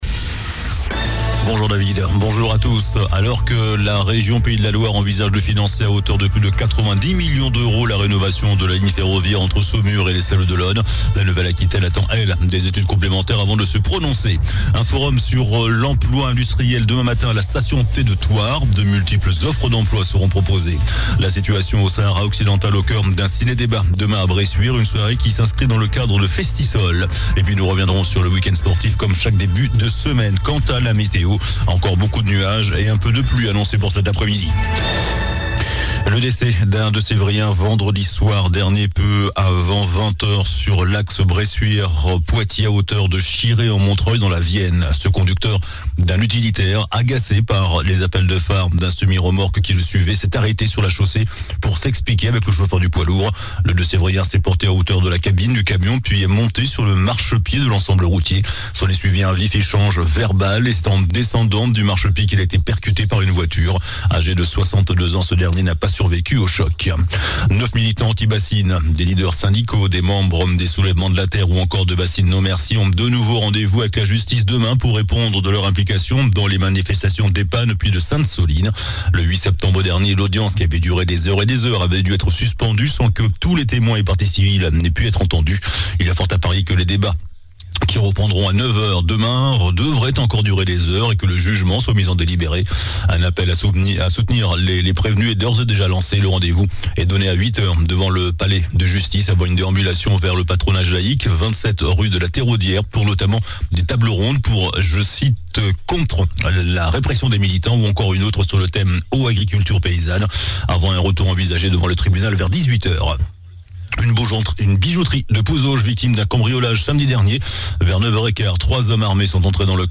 JOURNAL DU LUNDI 27 NOVEMBRE ( MIDI )